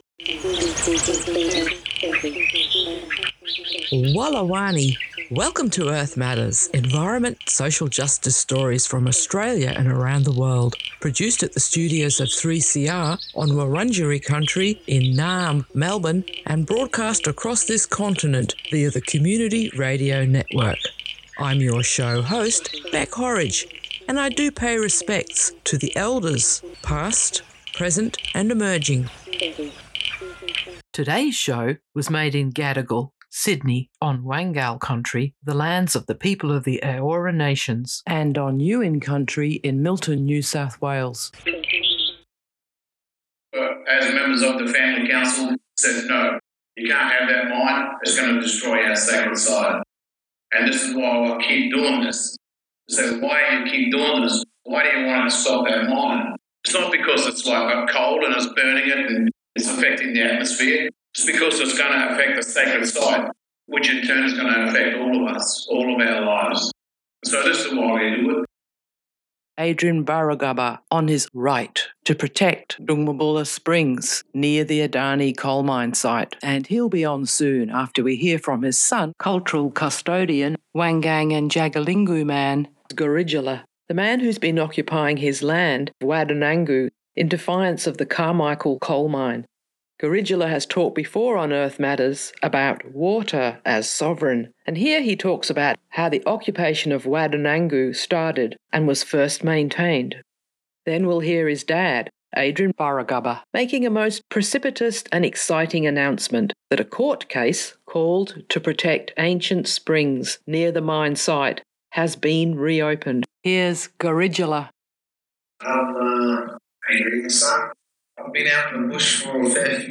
Part 1 of a webinar recorded last year by the World Fish Centre featuring small scale fisheries spokespeople from around the world, challenging one sided colonial narratives and centring fisher folk narratives.